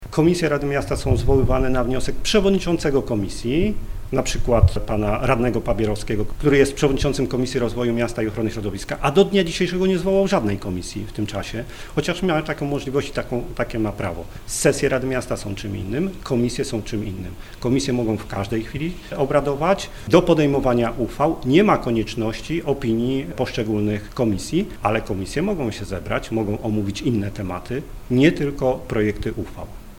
Tymczasem dziś przewodniczący Barczak przypomniał, że prowadzenie zdalnych sesji w formie nadzwyczajnej zostało uzgodnione ze wszystkimi stronami: